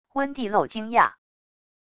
温蒂露惊讶wav音频